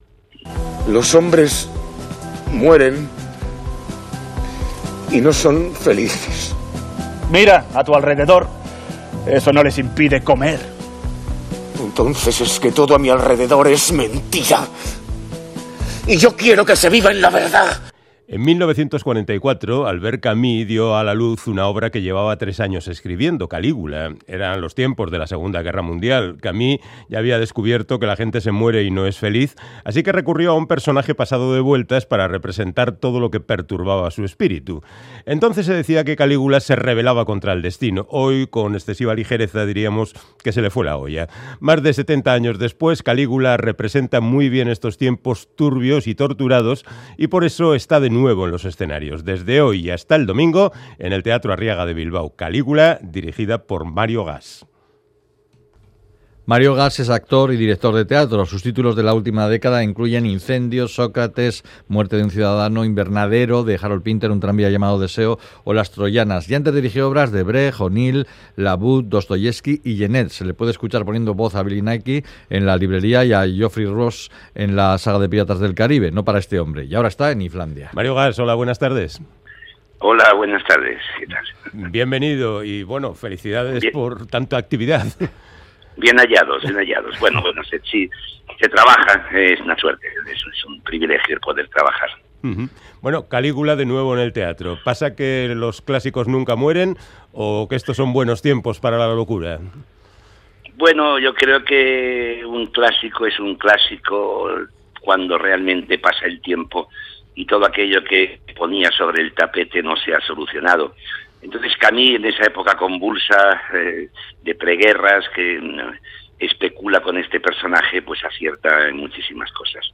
Audio: Charlamos con el director teatral Mario Gas, responsable del nuevo montaje de Calígula, un clásico del teatro del siglo XX que escribió el francés Albert Camus durante la II Guerra Mundial